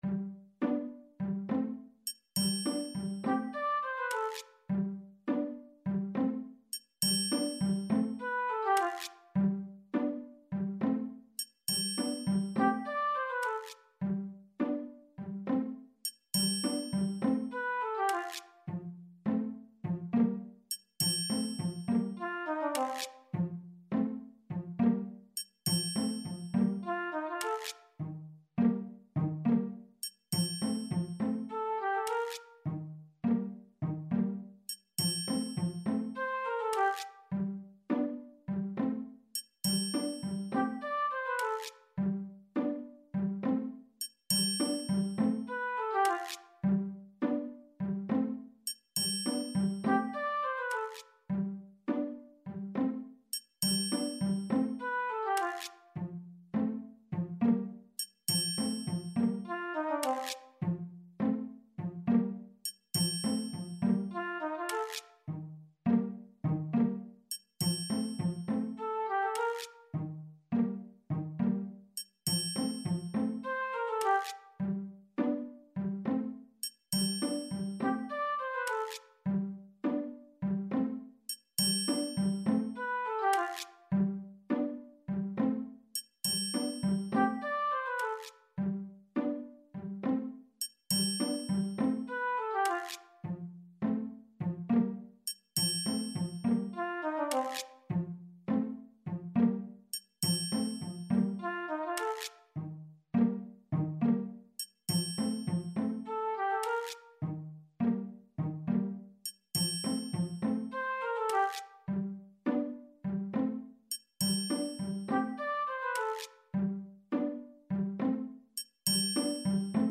あと音が目立ってはいけないって意味では会話シーンとかにも使えるかも。